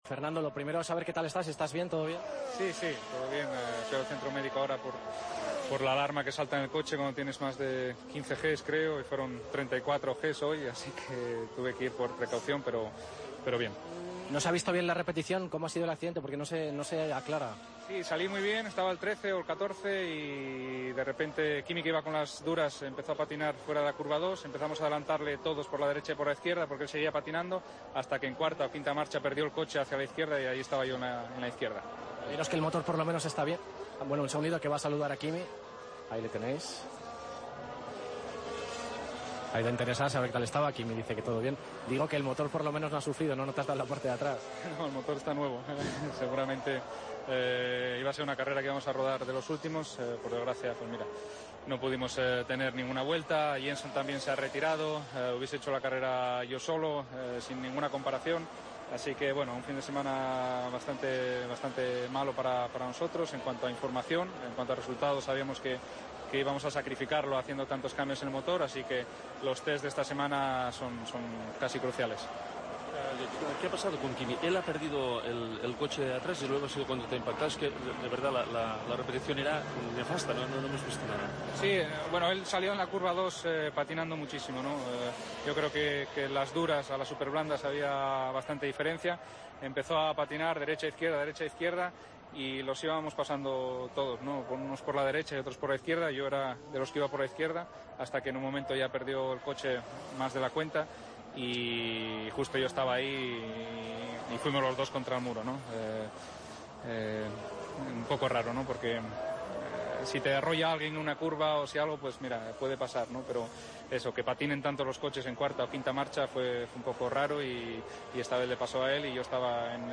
AUDIO: El piloto de McLaren explica cómo ha sido el accidente con Kimi Raikkonen en el GP de Austria.